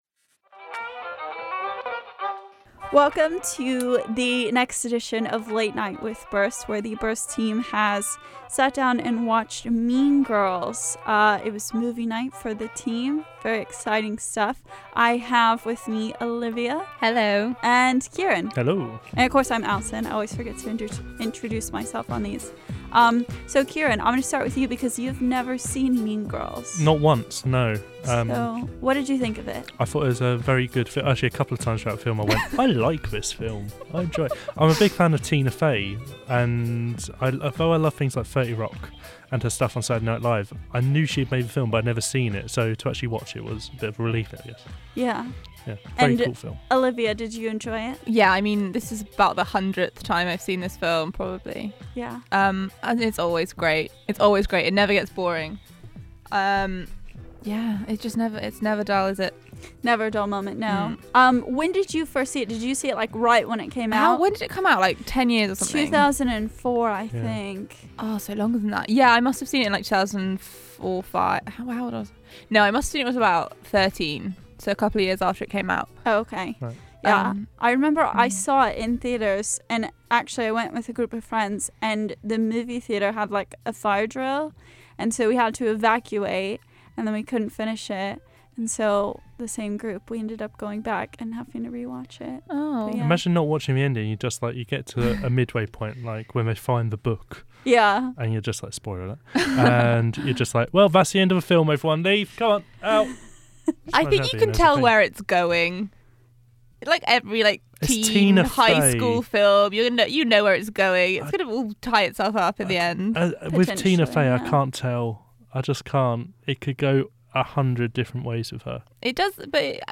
Remember on “Friday Meltdown” where the team was off to watch “Mean Girls.” Well that happened, and now we’ve decided to sit down and have a chat about it.